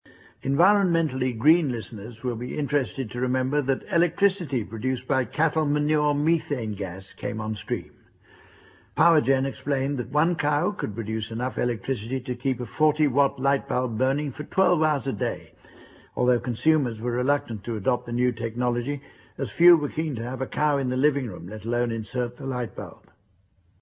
An excerpt from the radio 4 comedy show "I'm Sorry I Haven't A Clue" with Humphrey Lyttleton comparing and a variety of guests.